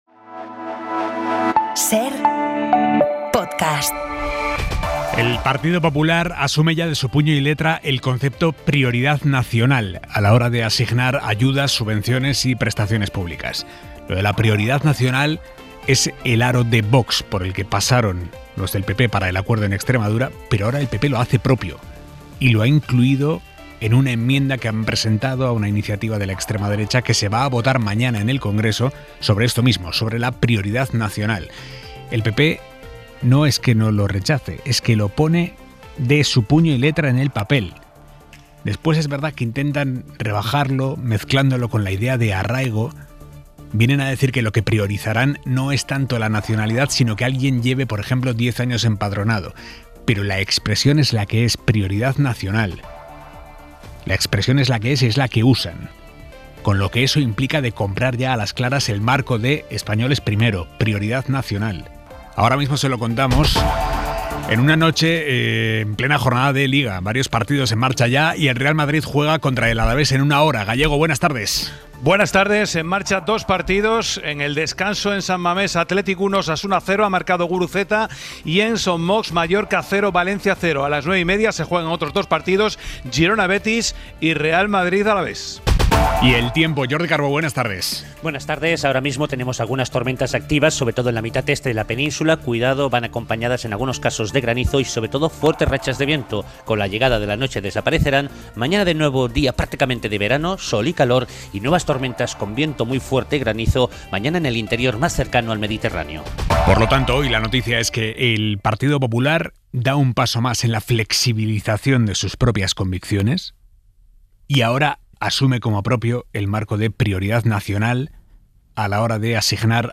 Las noticias que debes conocer esta tarde, con Aimar Bretos